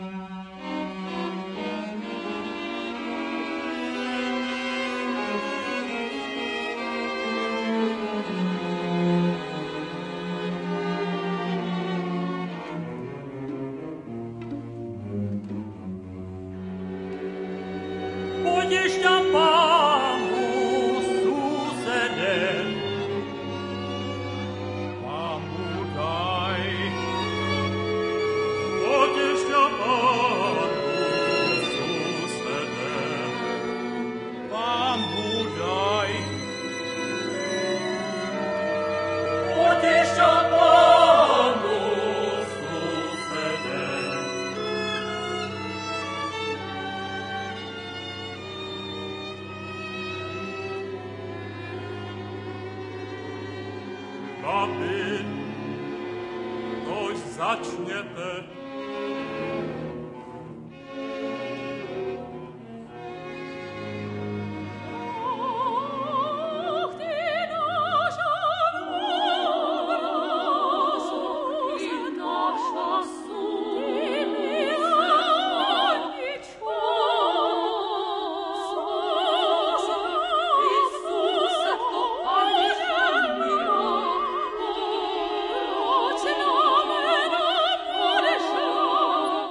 Matka/Mother, Quatertone Opera in 10 pictures, op. 35 (1929)